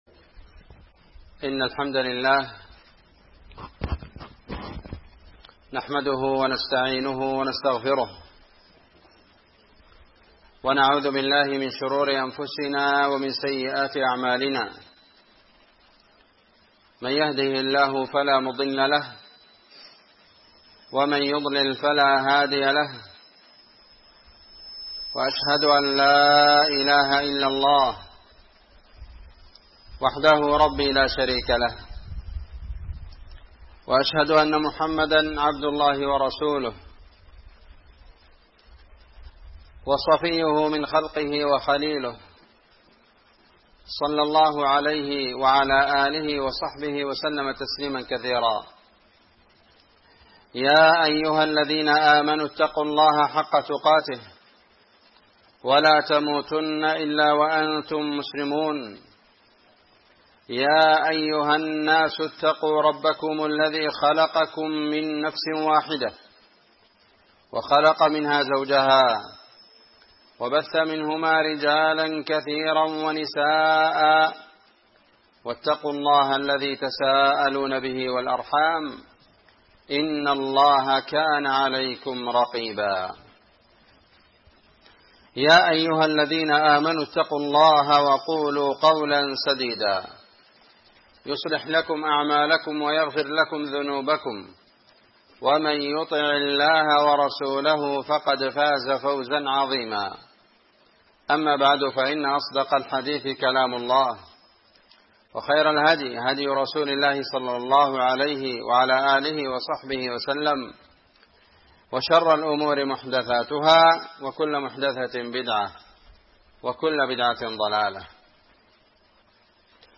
خطبة جمعة
إندونيسيا- جزيرة سولاويسي- مدينة بوني- قرية تيرونج- مسجد الإخلاص